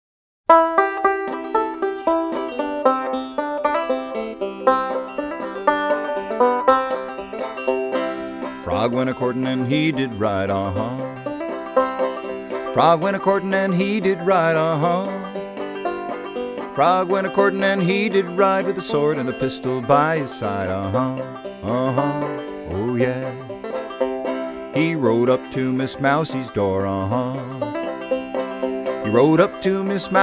Home > Folk Songs
spirited versions of old and new folk songs